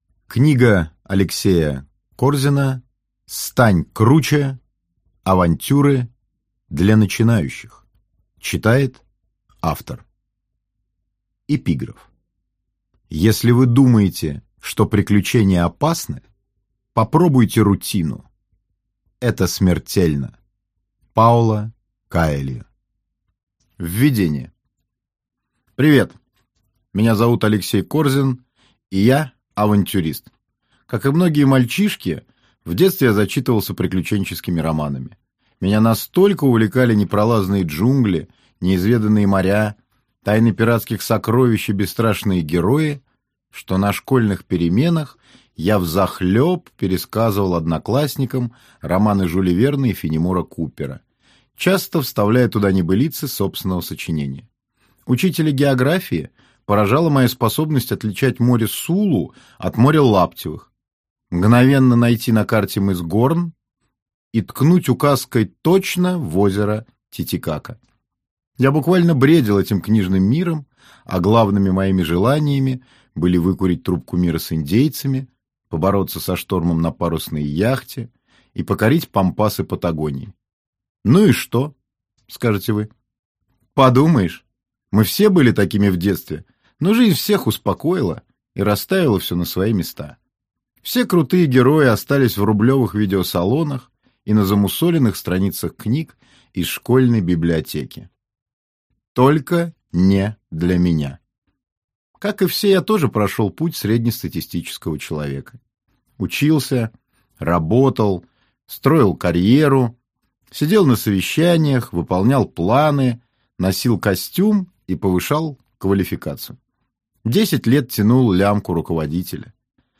Аудиокнига Стань круче! Авантюры для начинающих | Библиотека аудиокниг